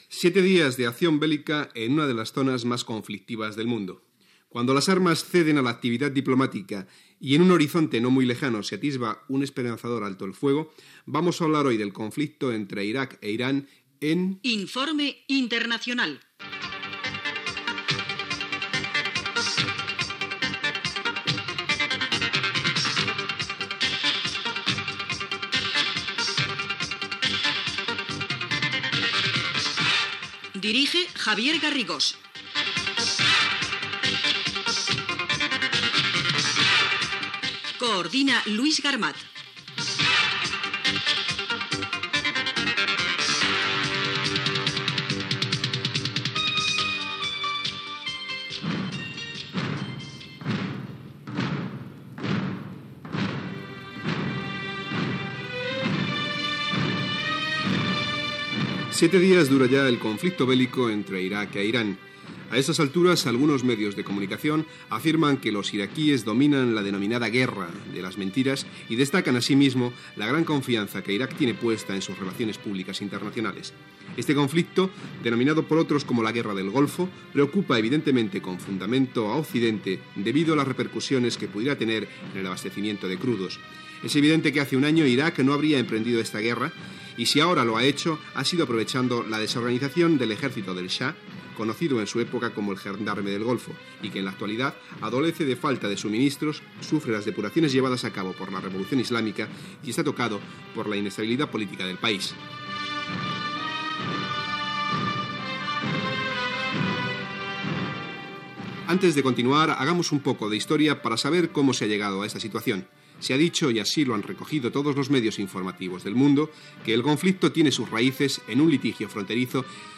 Presentació, careta del programa i inici de l'espai dedicat a la guerra entre Iraq i Iran set dies després del seu inici, el 22 de setembre de 1980
Informatiu